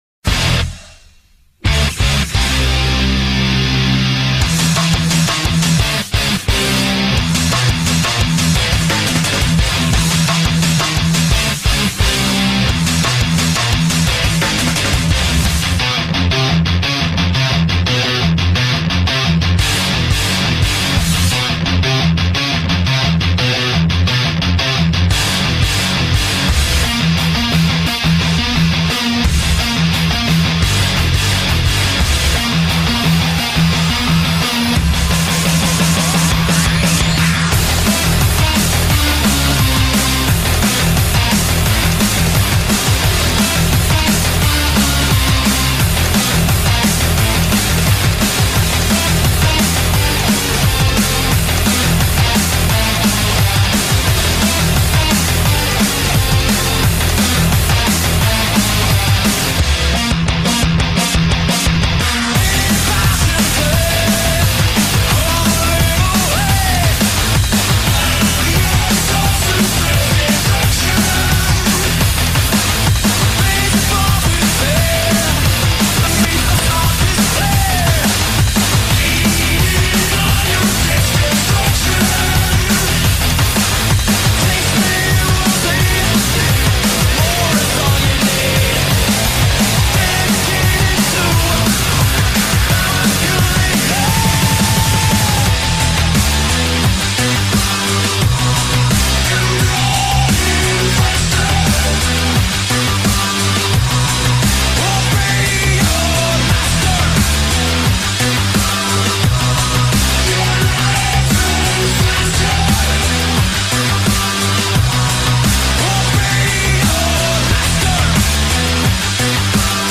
Стиль музыки: D'n'B